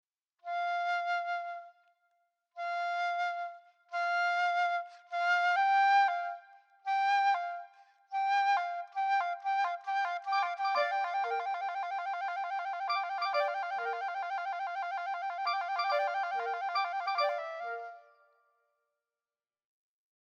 In the flute line we read “Nachtigall” (nightingale), the oboe is labelled “Wachtel” (quail), and the clarinets are the “Kukuk” (cuckoo) [1].
So here is my  rendition of Beethoven’s score for birds (at this point in the score, all the other instruments have stopped playing).
beethoven-6-all-3-birds-2cl.mp3